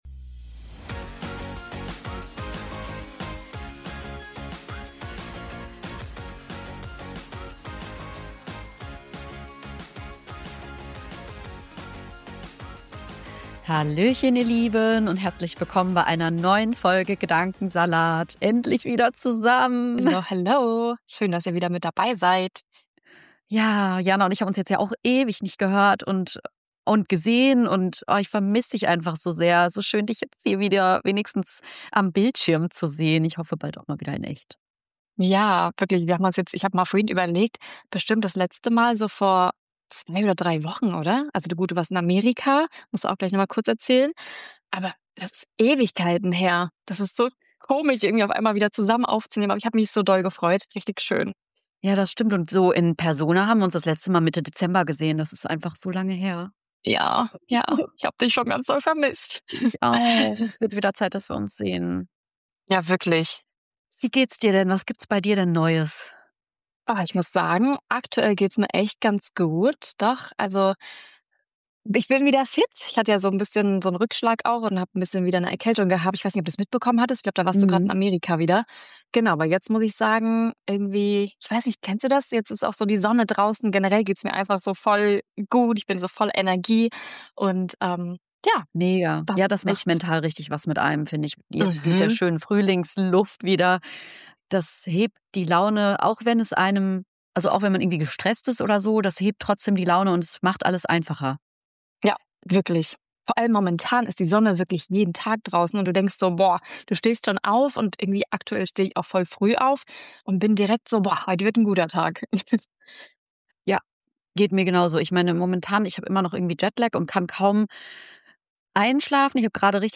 Zwischen Life-Updates, Gedanken aus dem Alltag und ein bisschen Abschweifen entsteht eine ganz entspannte Gesprächsfolge. Eine lockere Catch-up-Folge mit persönlichen Einblicken, ehrlichen Gedanken und ganz viel Gedankensalat.